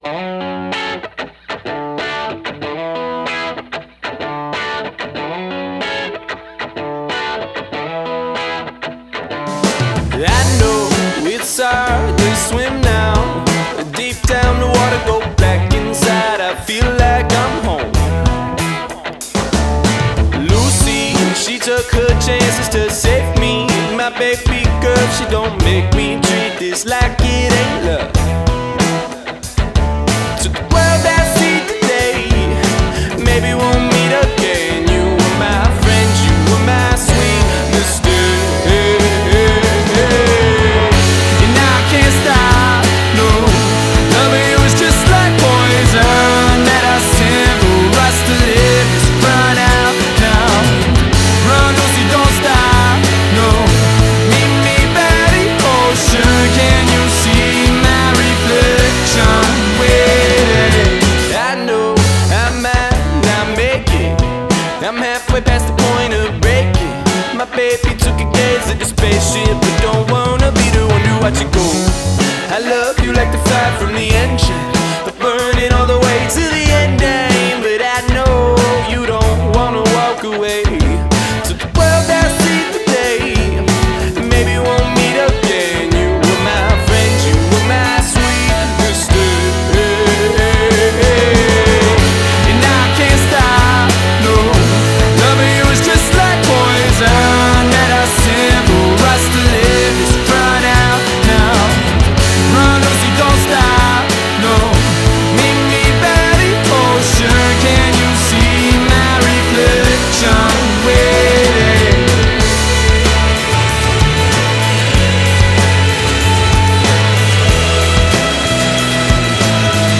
The funkified rock sounds
New York band